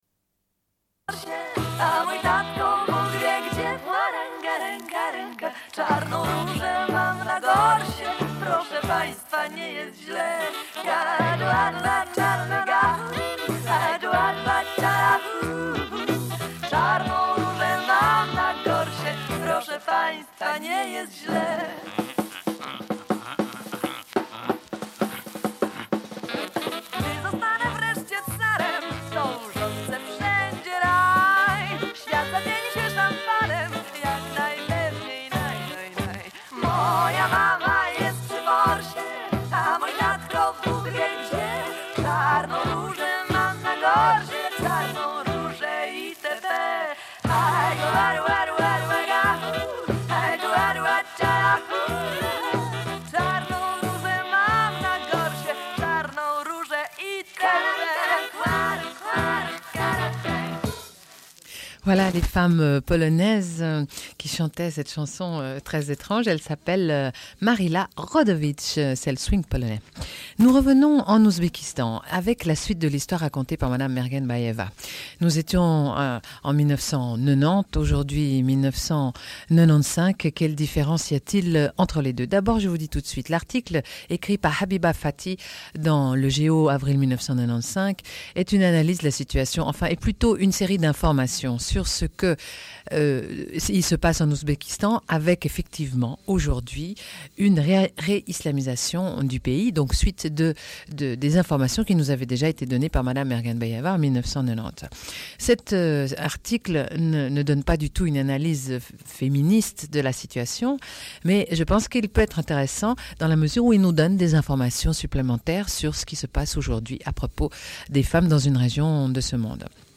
Une cassette audio, face B29:01
Radio